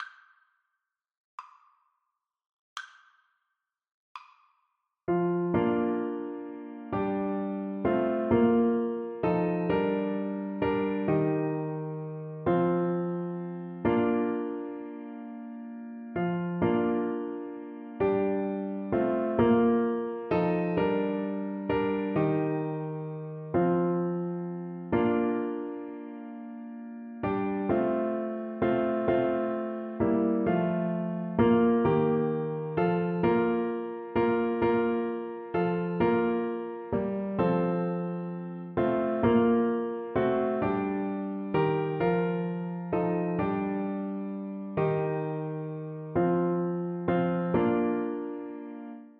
Christmas Trad. The Seven Joys of Mary Clarinet version
Clarinet
Traditional Music of unknown author.
6/8 (View more 6/8 Music)
F5-F6
Bb major (Sounding Pitch) C major (Clarinet in Bb) (View more Bb major Music for Clarinet )
Christmas (View more Christmas Clarinet Music)
seven_joysCL_kar3.mp3